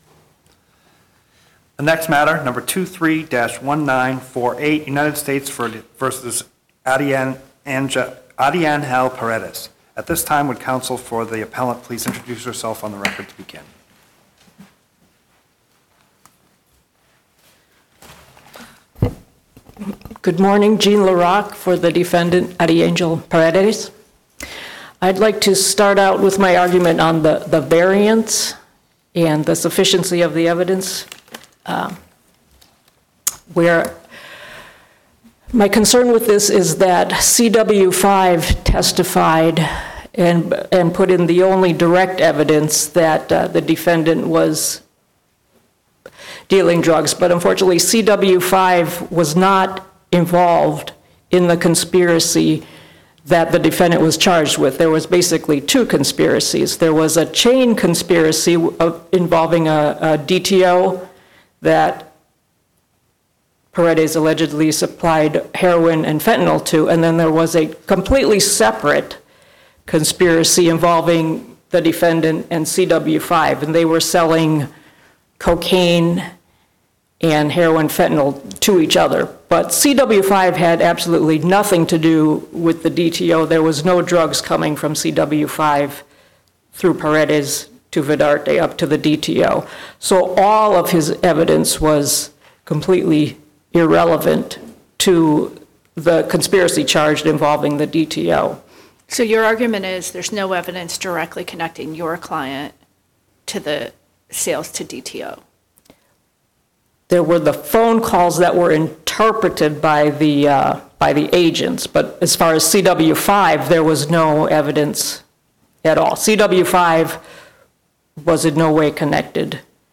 First Circuit Oral Arguments